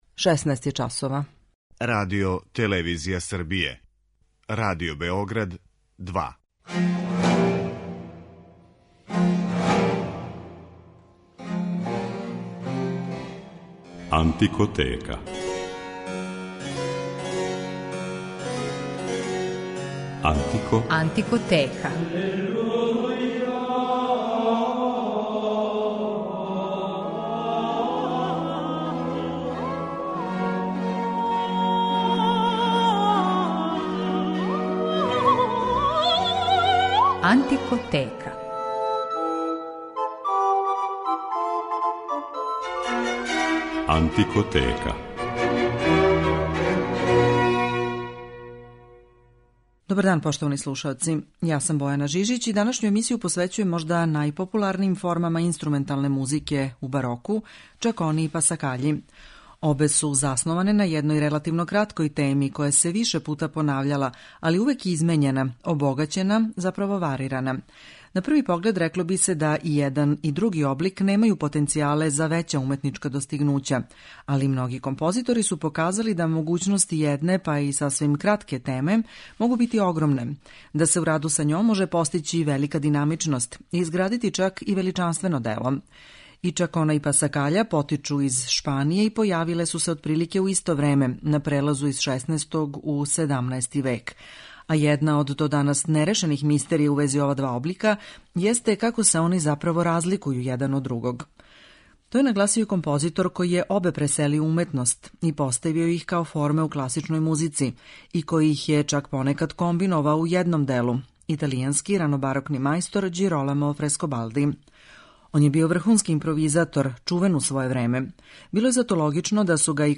моћи ћете да чујете како су чакону и пасакаљу музички обликовали неки од највећих барокних мајстора.